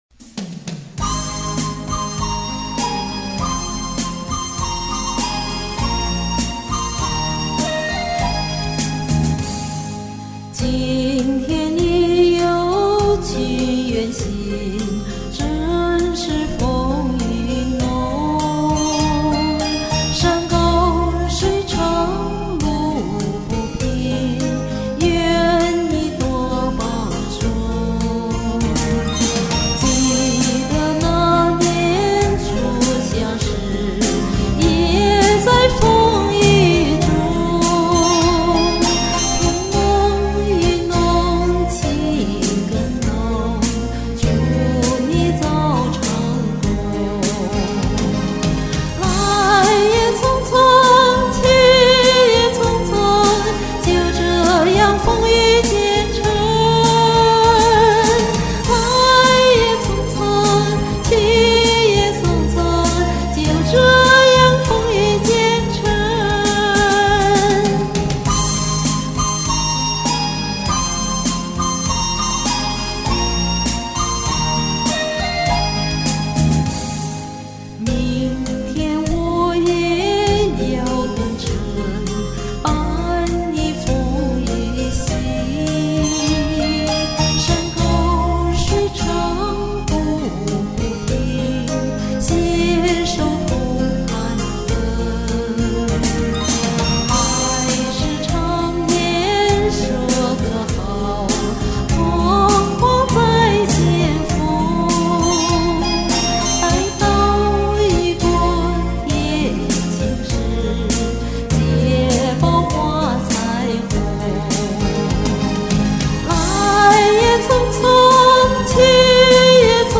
十年前的歌声